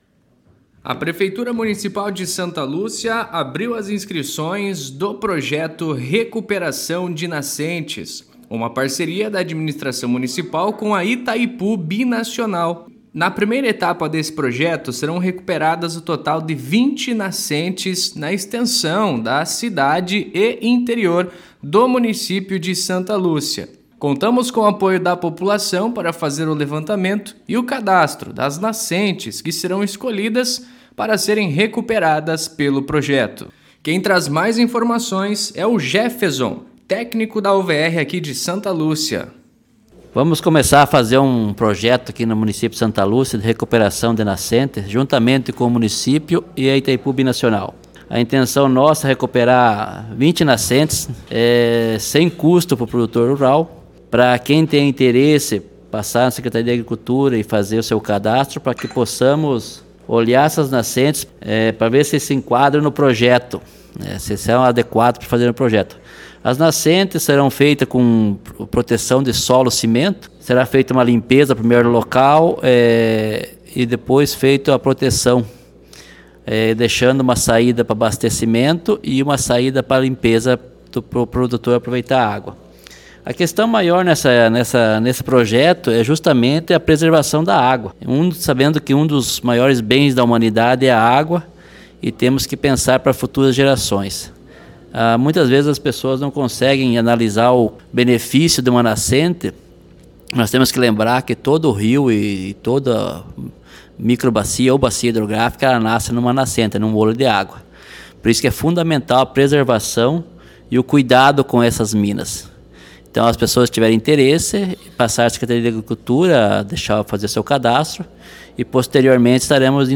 Mat�ria em �udio